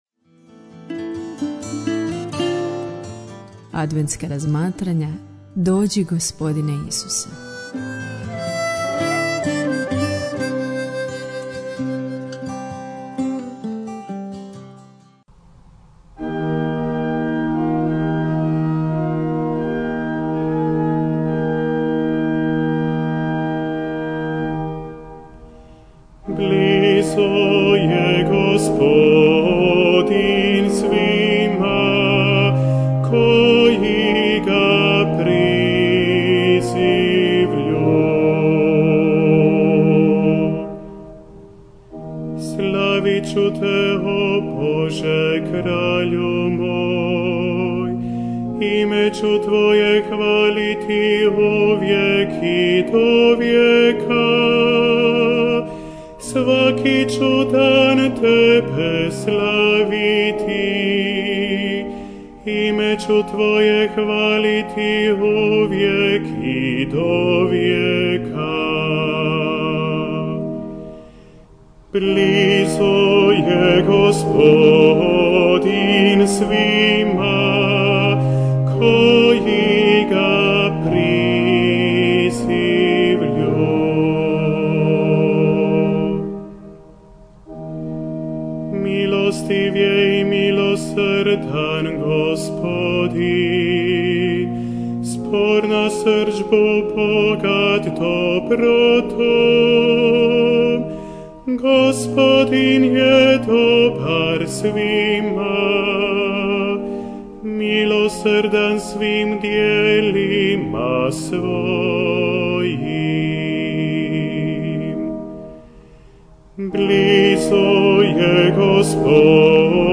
"Dođi Gospodine Isuse" - razmatranje uz 9. prosinca